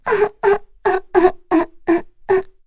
Kara-Vox_Sad.wav